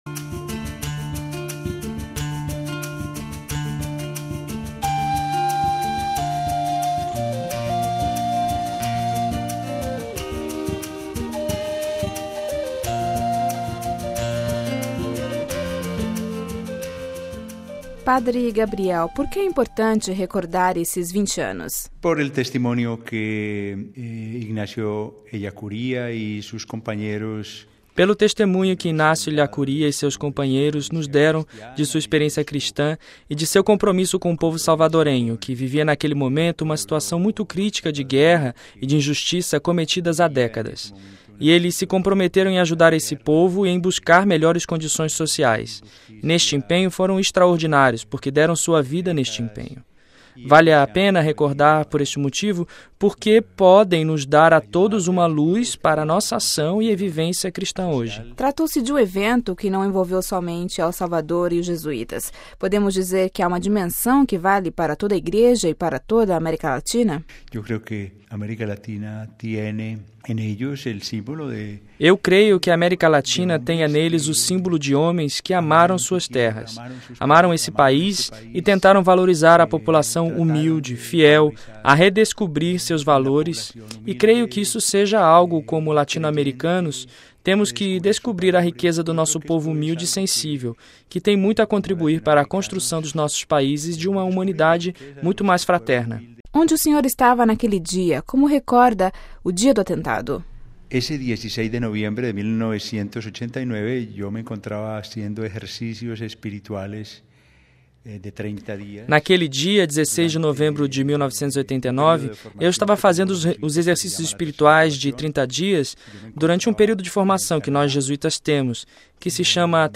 ENTREVISTA: ELLACURÍA E COMPANHEIROS NOS ENSINAM COMO ENCARNAR O EVANGELHO